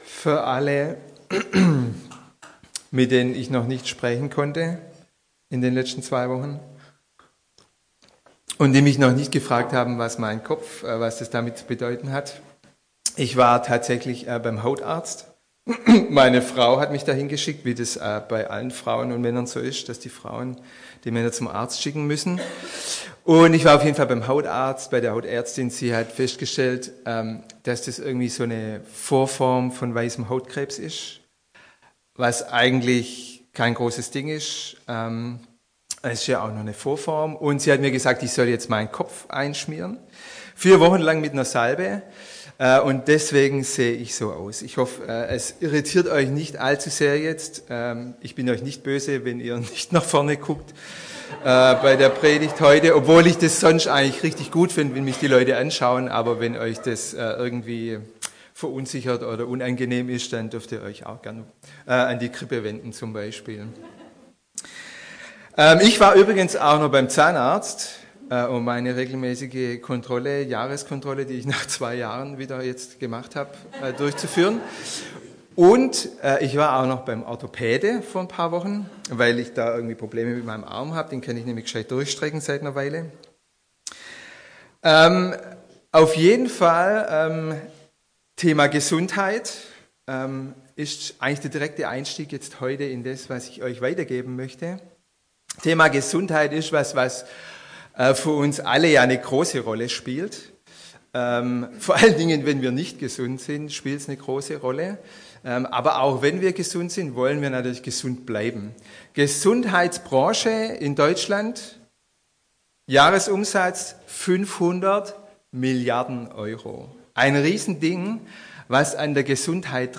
Predigten aus der Fuggi